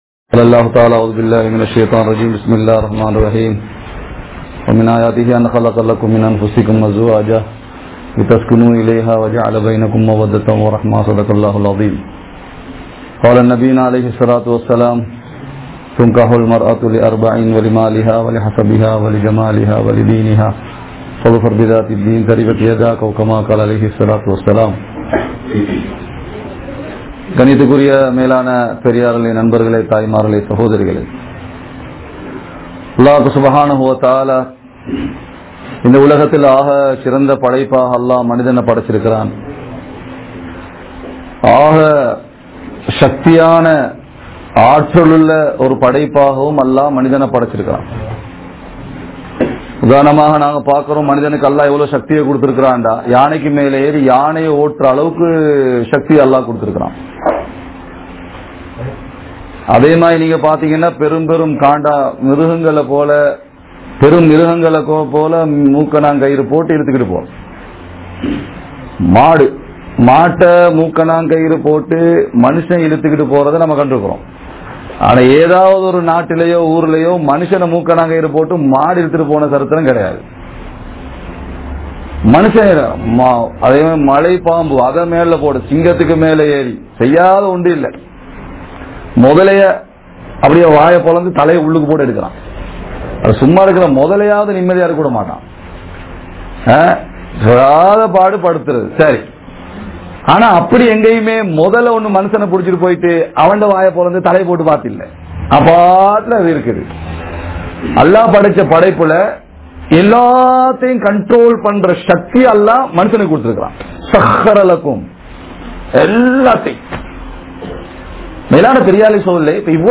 Illara Vaalkai (இல்லற வாழ்க்கை) | Audio Bayans | All Ceylon Muslim Youth Community | Addalaichenai